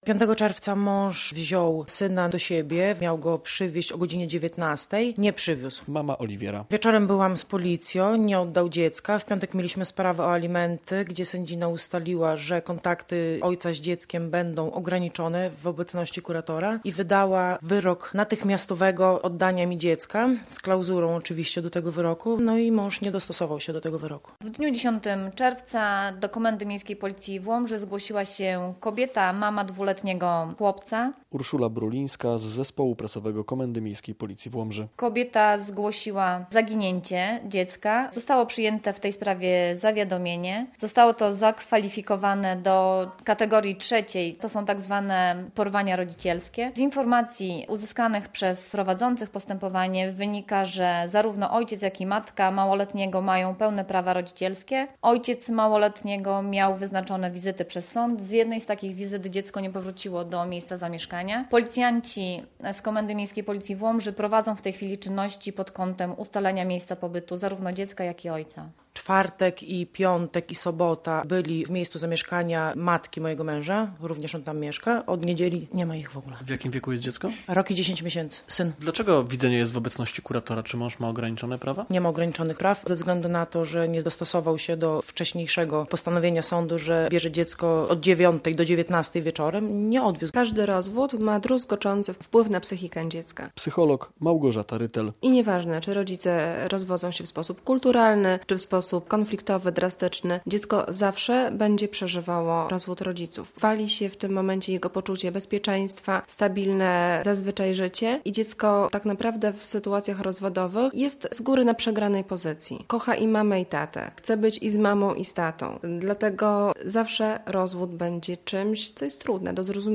Porwanie rodzicielskie w Łomży - relacja